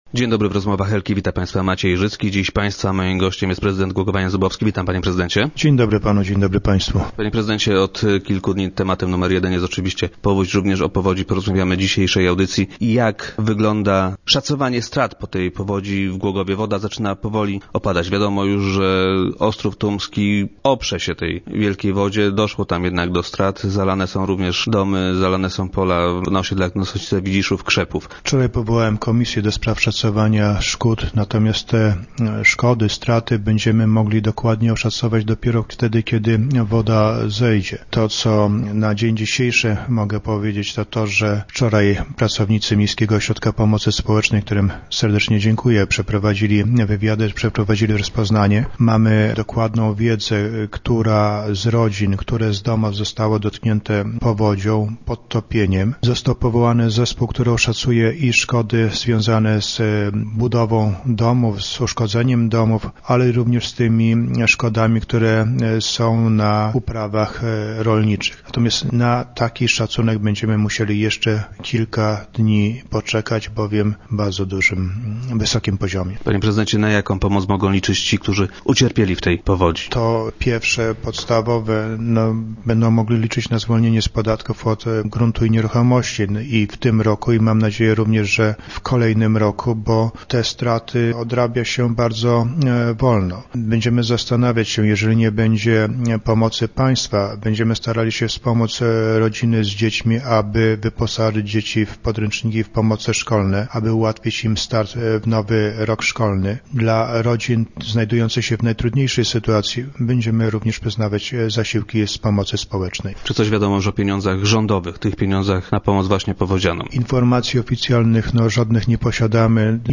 - Pracownicy Miejskiego Ośrodka Pomocy Społecznej dotarli już do niemal wszystkich poszkodowanych rodzin - informuje prezydent, który był dziś gościem Rozmów Elki.